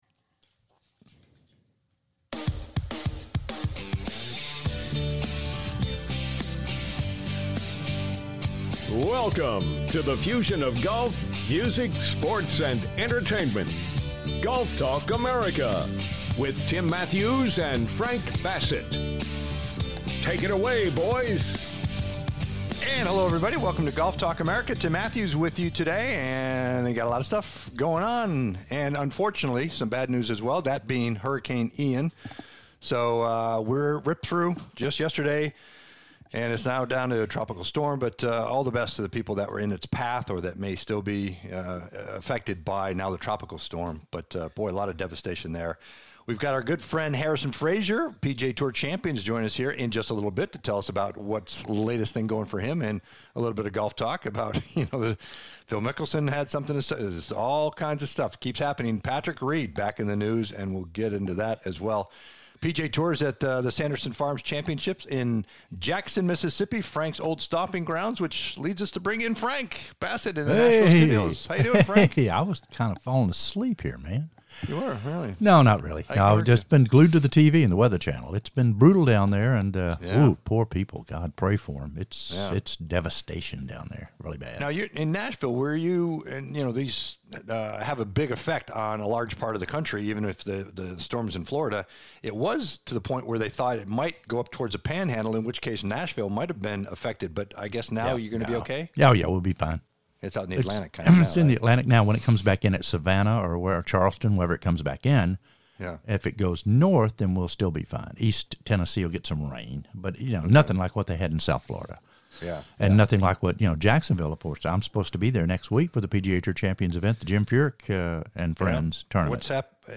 HARRISON FRAZAR, PGA TOUR CHAMPION JOINS THE CONVERSATION